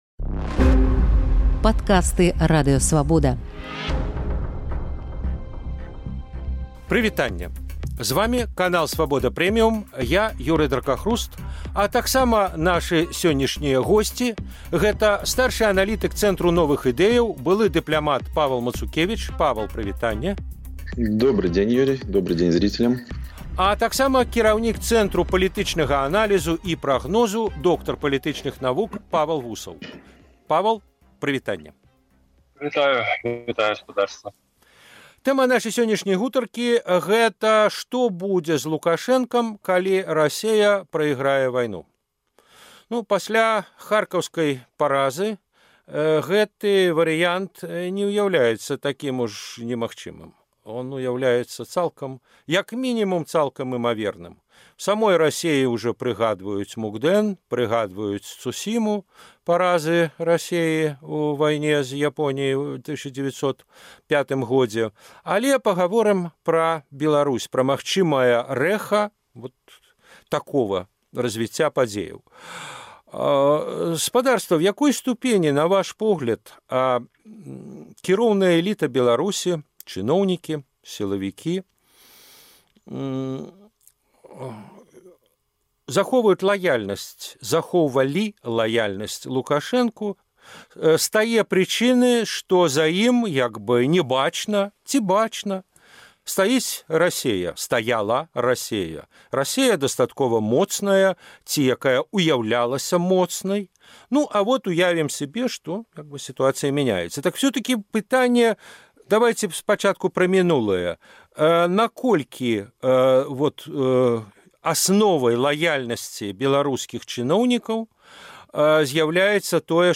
Што будзе з Лукашэнкам у выпадку паразы Расеі ў вайне? Дыскусія аналітыкаў